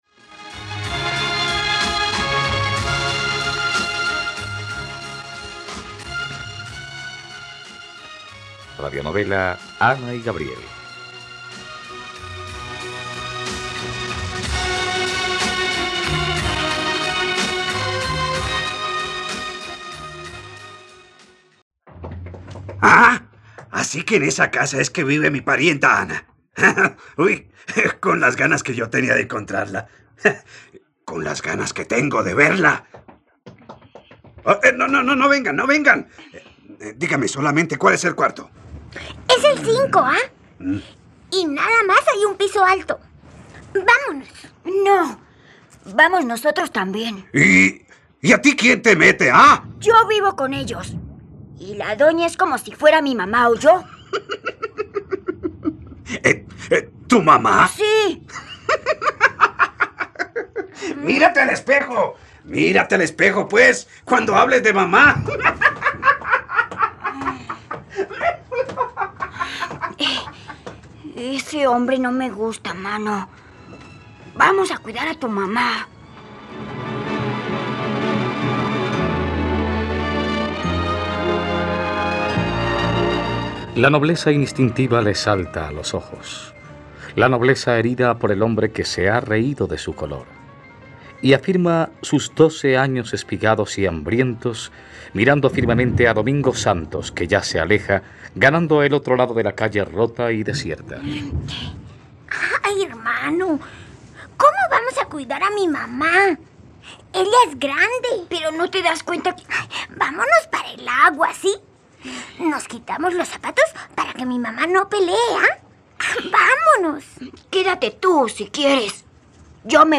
..Radionovela. Escucha ahora el capítulo 110 de la historia de amor de Ana y Gabriel en la plataforma de streaming de los colombianos: RTVCPlay.